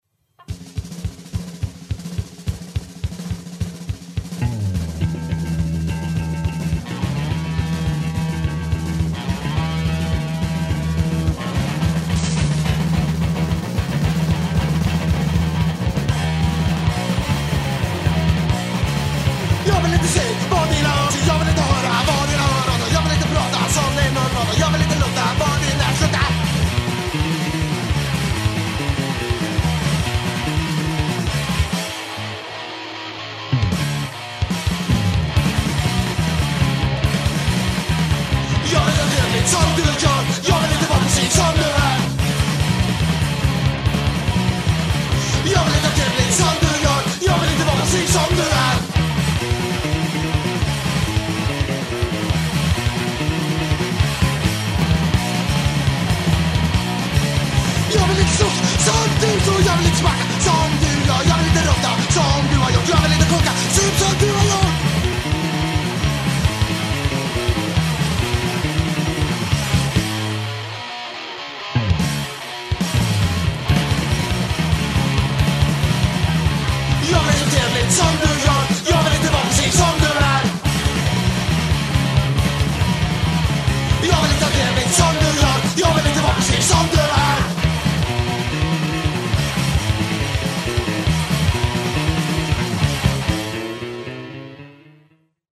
a quartet, who tried to play fast punk
Guitar, voice
Drums